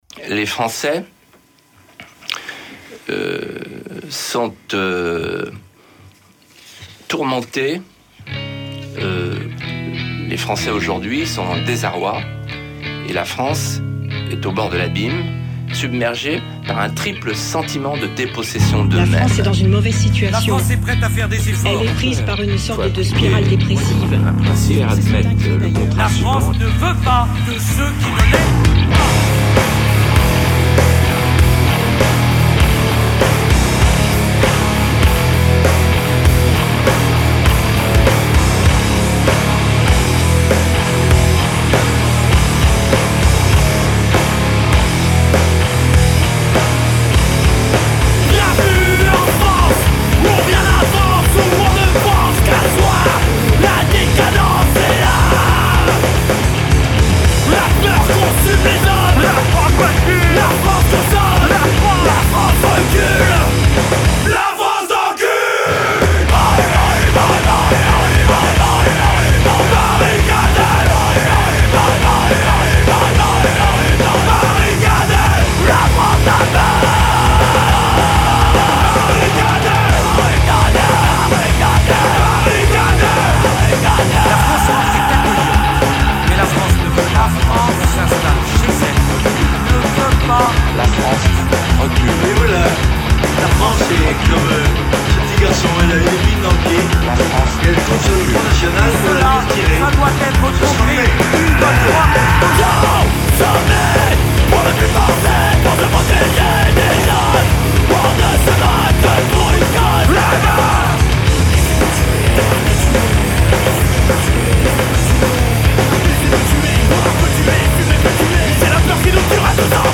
punk core très old school
avec un solo de guitare complétement décalé